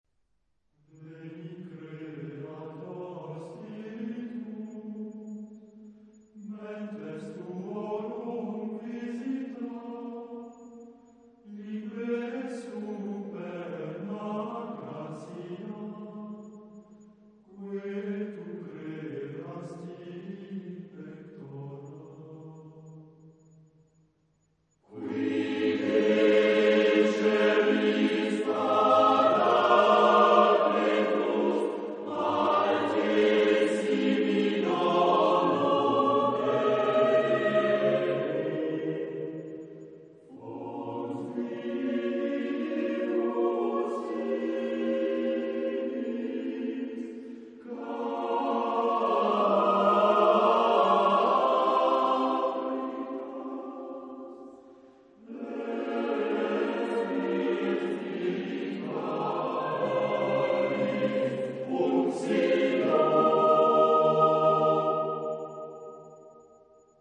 Genre-Style-Forme : Motet ; Sacré
Type de choeur : SATB  (4 voix mixtes )
Tonalité : la majeur